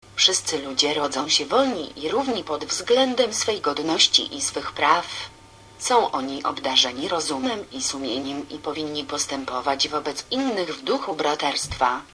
Text to speech
The quality of the speech on these sites varies – for some languages it sounds fairly natural, for others it sounds artificial.
🙂 The Polish (I assume- It looks like Polish to me) and Arabic both sound robotic- Especially the Arabic version.